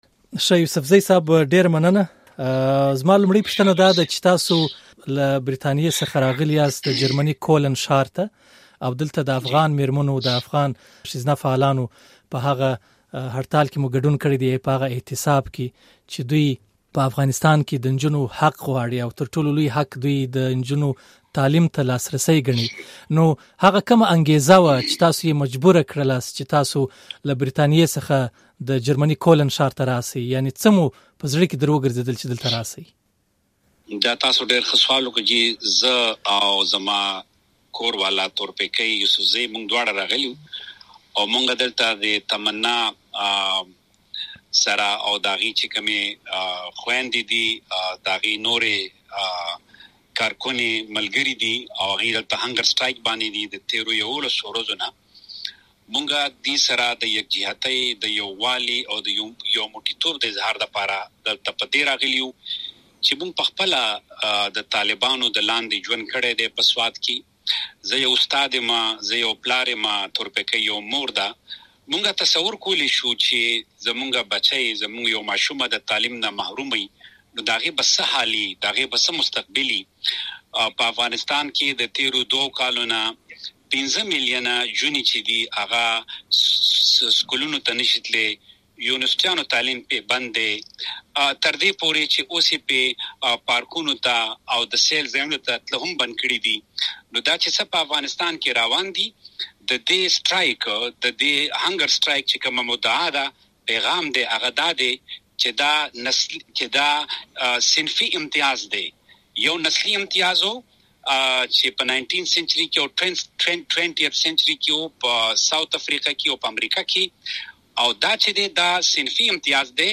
نوموړي دا خبره د سېپټېمبر پر ۱۱مه د جرمني په کولن ښار کې د یو شمېر افغان ښځینه فعالانو لخوا د نه خوړلو په اعتصابي کېمپ کې د ګډون پر مهال مشال راډيو ته وکړه. يوسفزي زياته کړه چې نړۍ بايد پر طالبانو فشار راوړي چې له ښځو سره د هغوی د صنفي امتياز تګلاره پای ته ورسوي.